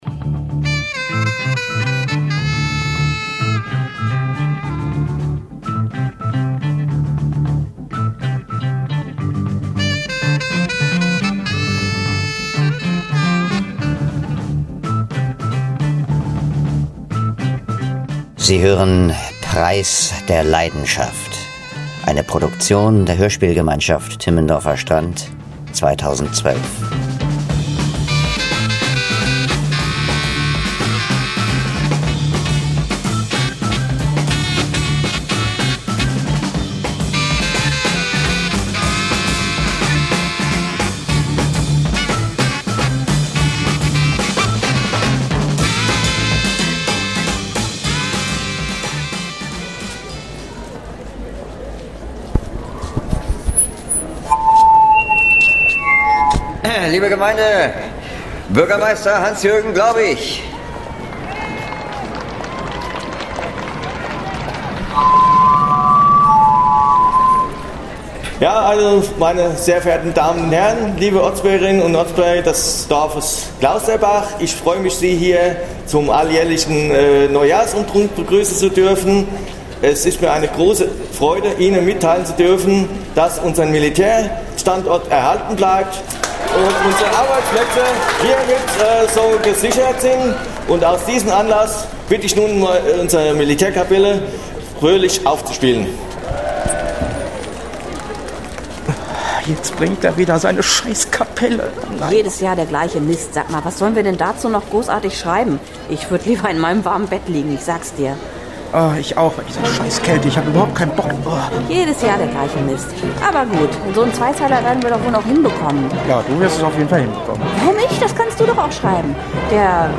Produktion der Hörspielgemeinschaft Timmendorfer Strand 2012